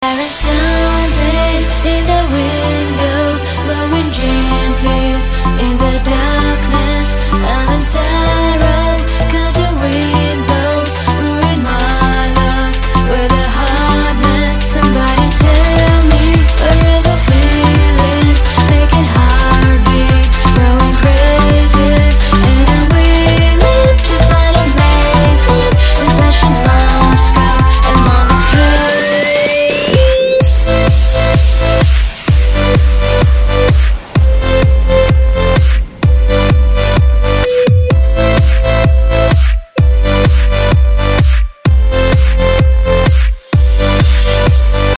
Танцевальные [47]